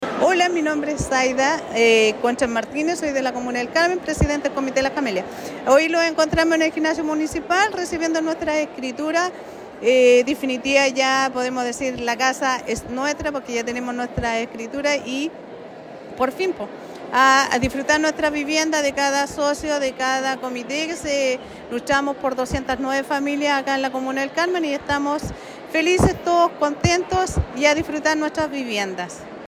En una emotiva ceremonia realizada en el gimnasio municipal de El Carmen, 209 familias recibieron las escrituras de sus viviendas, marcando el cierre de un largo proceso que se inició hace más de dos décadas.